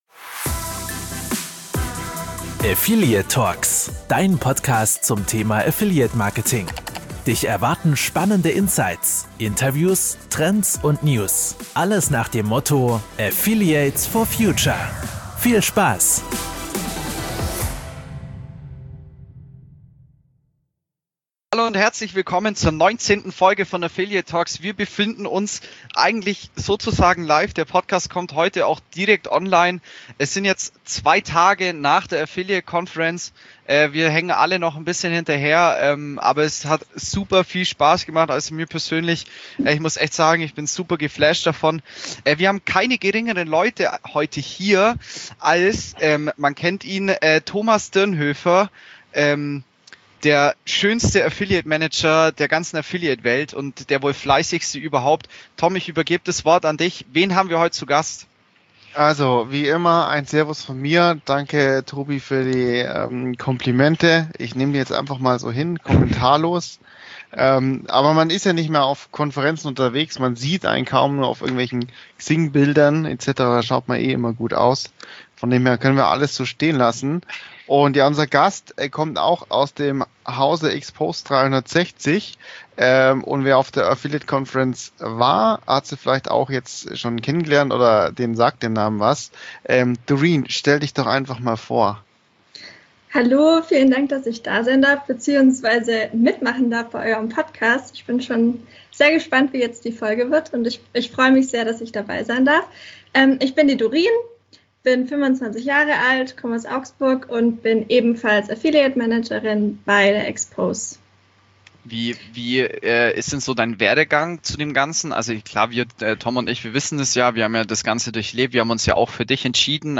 Affiliate TalkxX 19: Die Affiliate Conference (Rückblick) // Interview